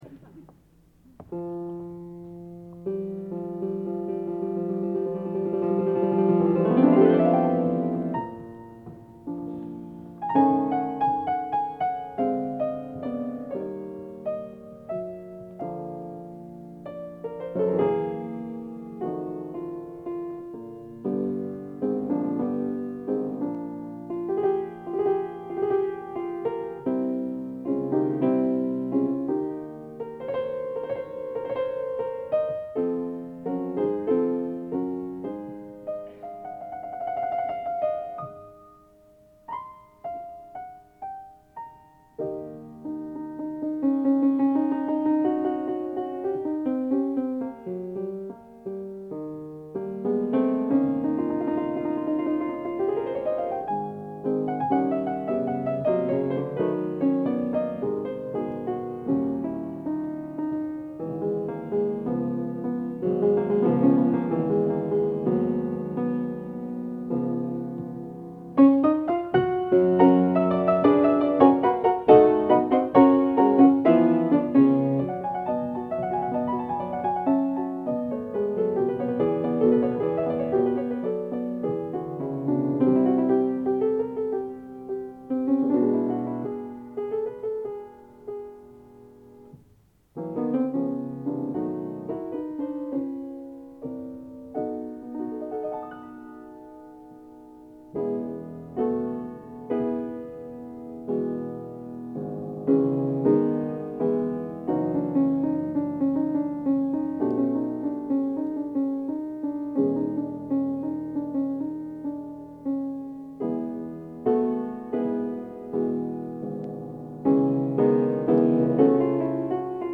Collection: Plymouth, England
Location: Plymouth, England